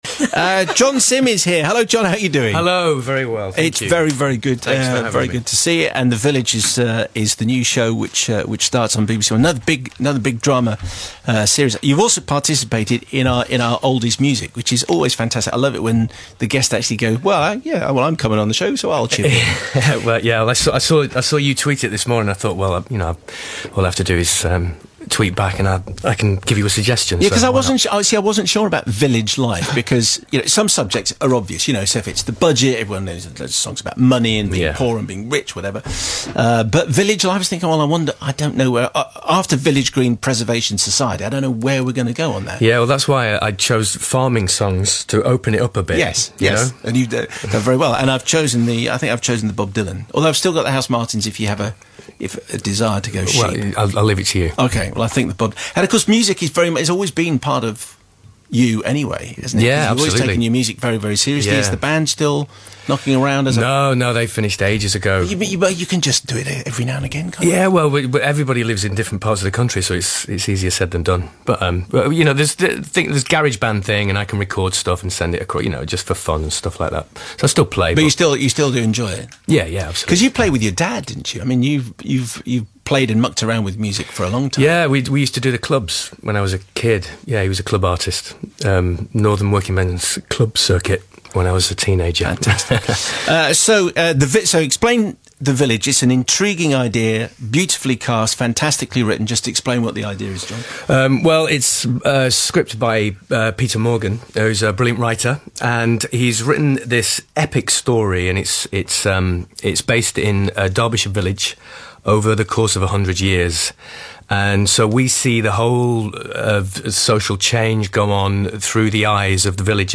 Radio Interview: Simon Mayo with John Simm
Simon Mayo is joined by actor John Simm who is starring in new BBC drama The Village alongside Maxine Peake.